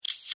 B_04_CLIC.mp3